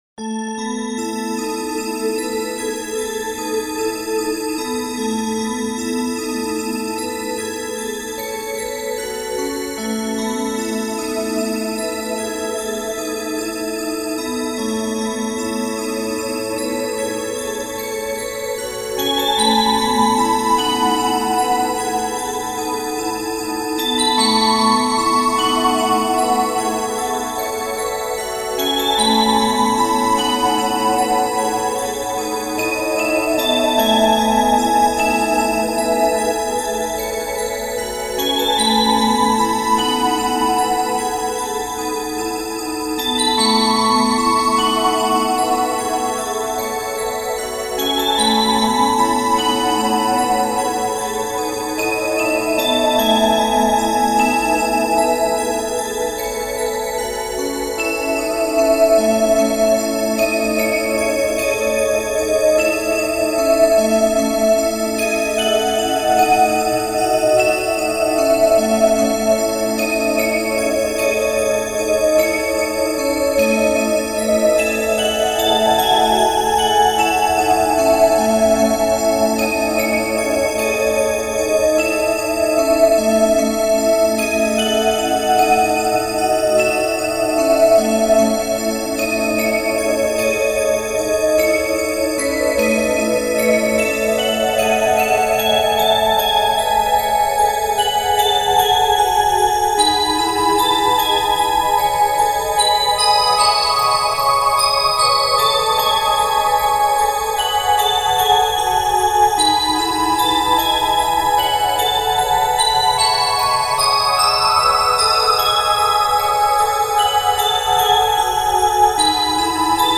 フリーBGM イベントシーン 幻想的・神秘的